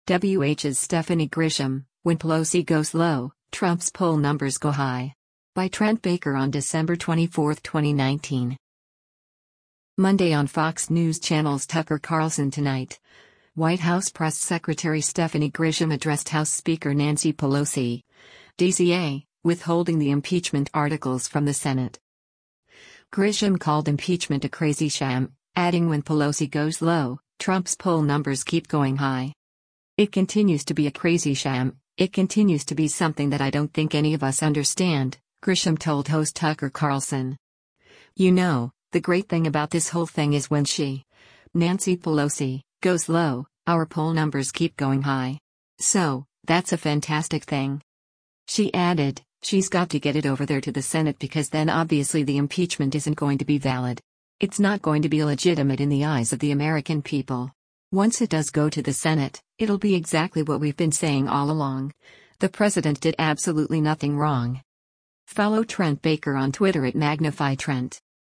Monday on Fox News Channel’s “Tucker Carlson Tonight,” White House press secretary Stephanie Grisham addressed House Speaker Nancy Pelosi (D-CA) withholding the impeachment articles from the Senate.